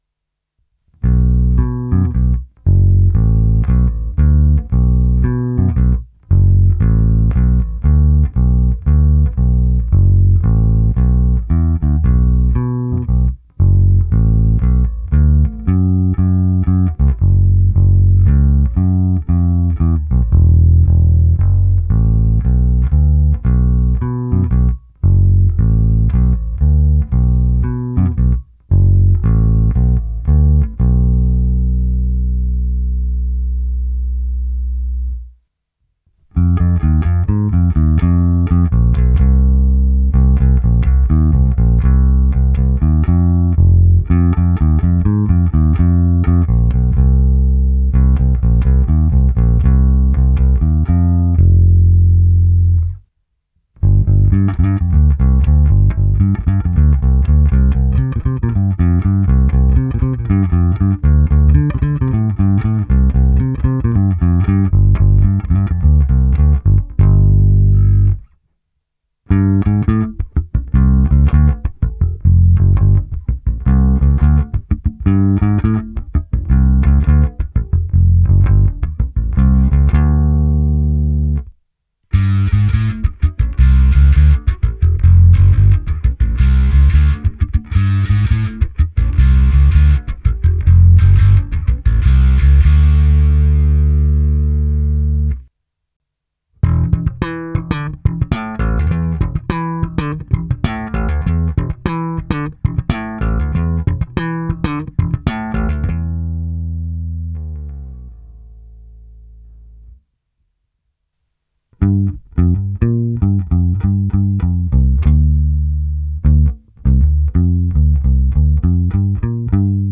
Zvuk je poctivý Precision, ten z nejpoctivějších.
Tónová clona je stažená cca o 1/4.